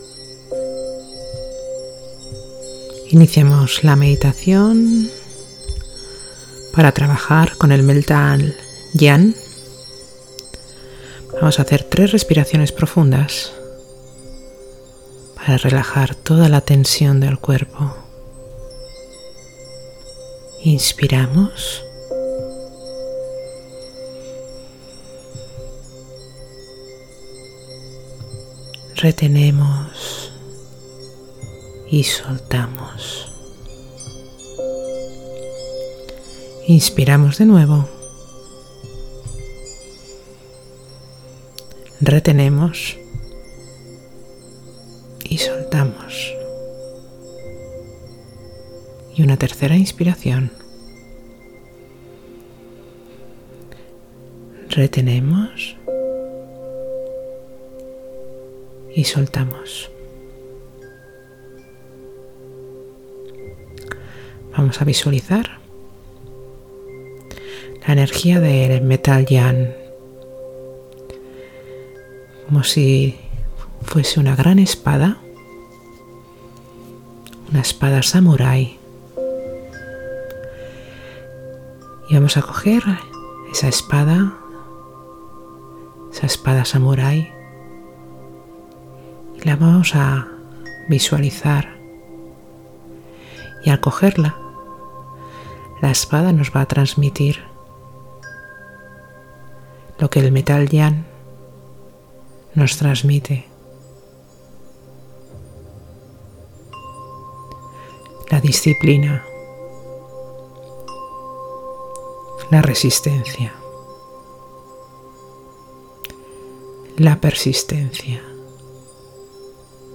Meditación – Metal Yang